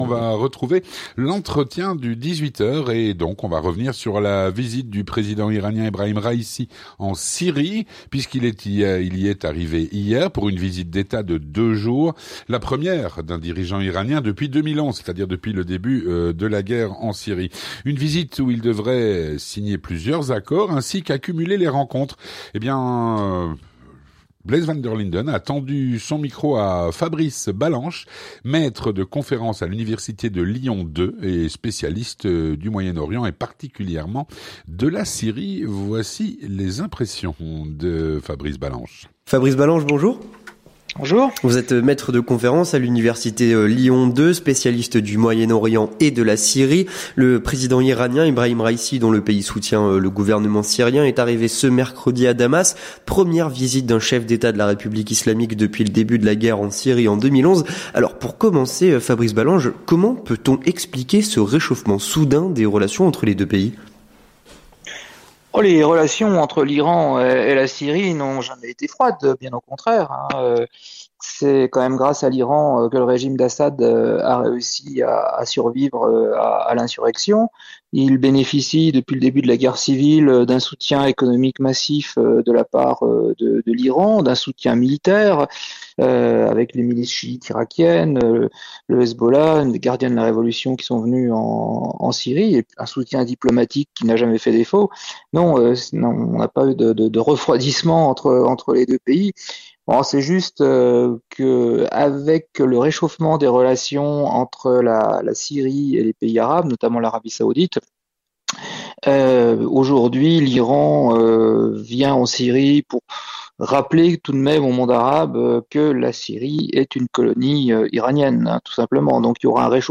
Entretien du 18h - La visite d'Ebrahim Raïssi en Syrie